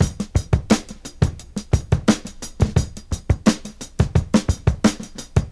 • 87 Bpm Drum Beat E Key.wav
Free drum loop - kick tuned to the E note. Loudest frequency: 1226Hz
87-bpm-drum-beat-e-key-ytk.wav